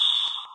whoosh_down_1.ogg